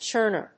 churner.mp3